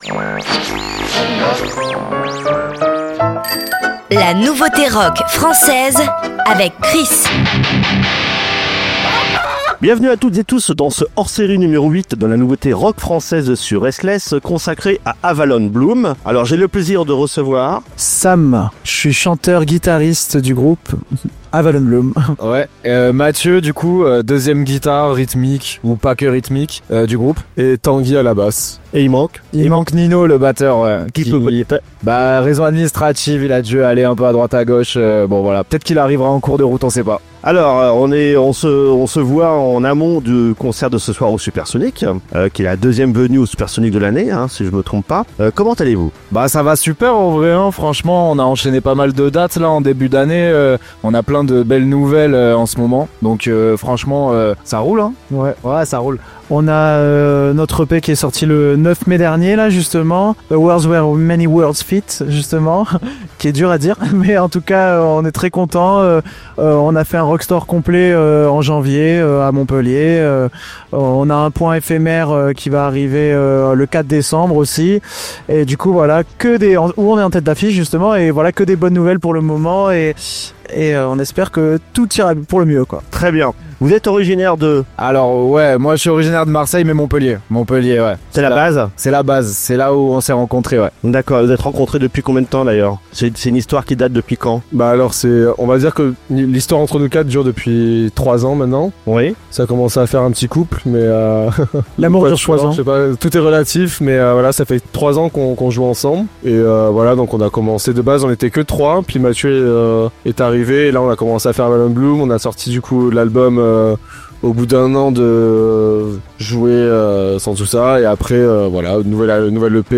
La Nouveauté Rock Française RSTLSS Hors Série #8 - AVALON BLOOM Play Episode Pause Episode Mute/Unmute Episode Rewind 10 Seconds 1x Fast Forward 30 seconds 00:00 / 00:29:41 Subscribe Share RSS Feed Share Link Embed